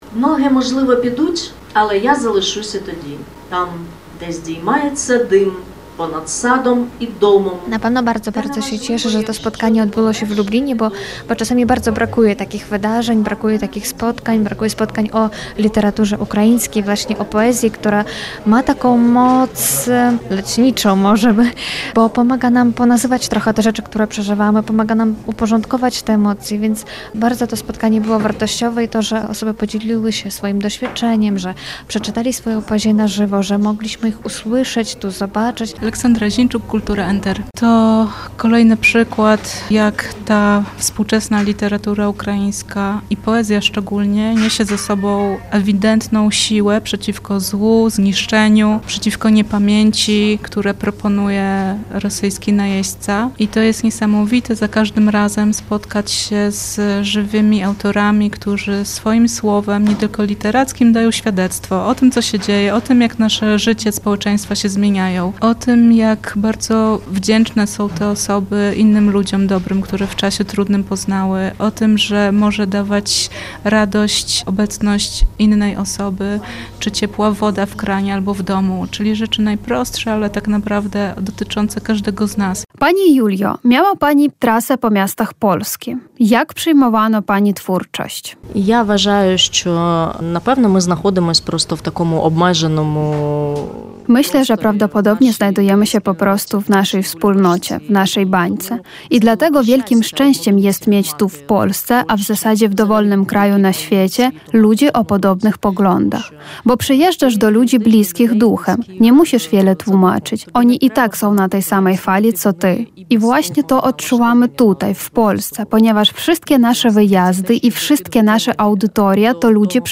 W Lublinie odbył się Wieczór z ukraińską poezją.
Podczas spotkania poetki zaprezentowały swoje utwory w dwóch językach – po polsku i po ukraińsku. Wieczór był również okazją do rozmowy z autorkami oraz refleksji nad rolą poezji i słowa w czasie wojny.